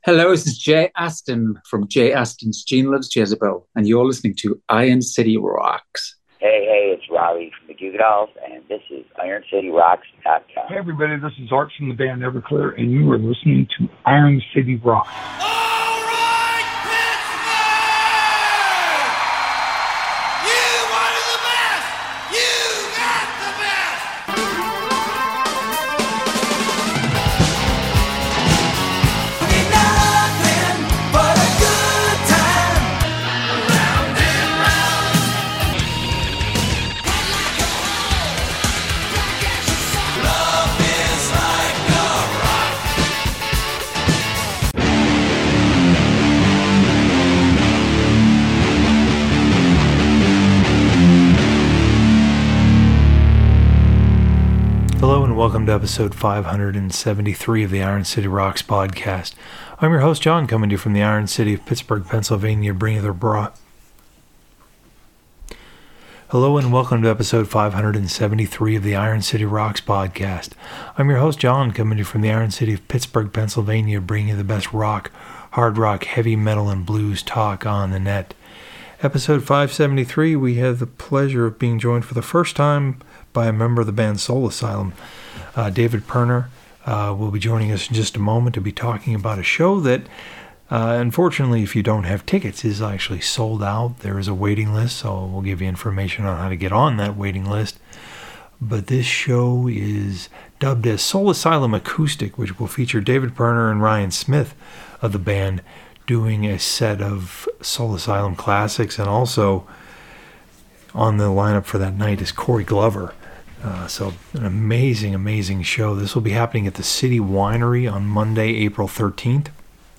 In Episode 573 of the Iron City Rocks Podcast we welcome Soul Asylum’s David Pirner.